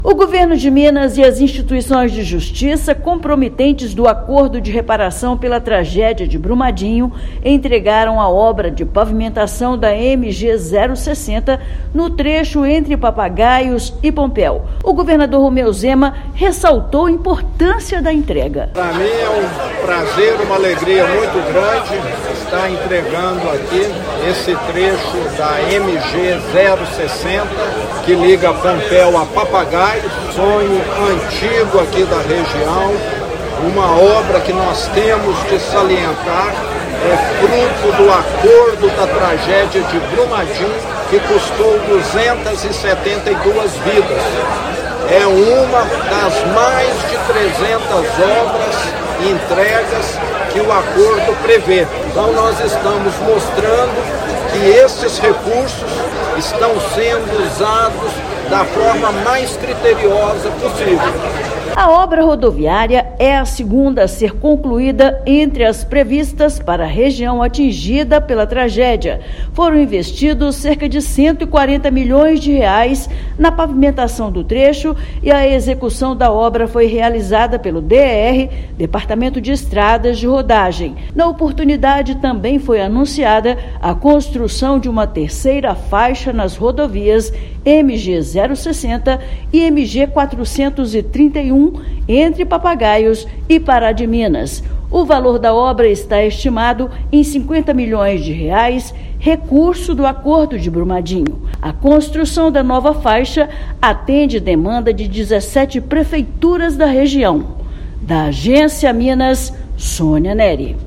Esta é a segunda obra viária concluída da lista das destinadas à melhoria da mobilidade na Bacia do Paraopeba, que será ampliada, chegando a oito intervenções. Ouça matéria de rádio.